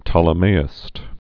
(tŏlə-māĭst)